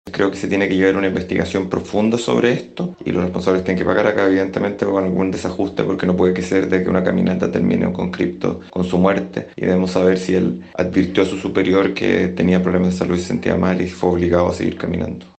Por otra parte, el diputado independiente de la bancada socialista, Tomás de Rementería, señaló que no puede ser que una caminata haya provocado la muerte de uno de los conscriptos, por lo que a su juicio, evidentemente hubo un “desajuste”.